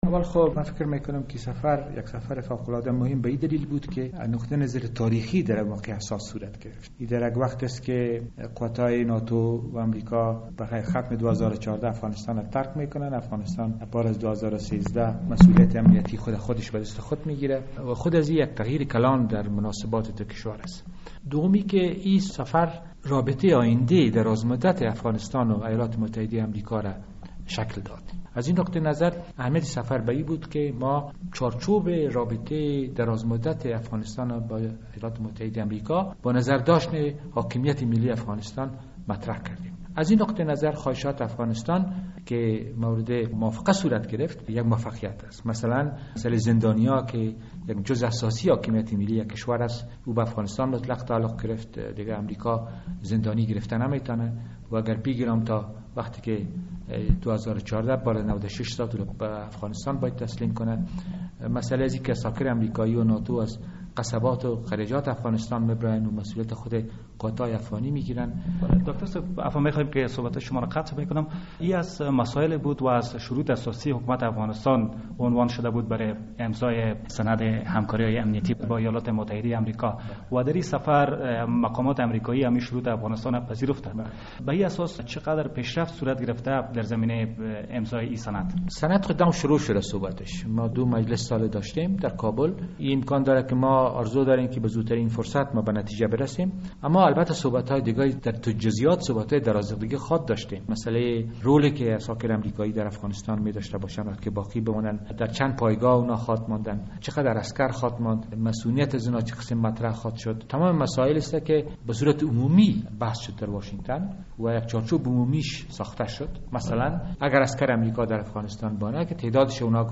وزیر خارجه افغانستان ابراز امیدواری می کند که کابل و واشنگتن به زودی در راستای امضای سند امنیتی به توافق برسند. داکتر زلمی رسول در مصاحبه با رادیو آزادی گفت که در این مورد در جریان سفر اخیر رییس جمهور کرزی...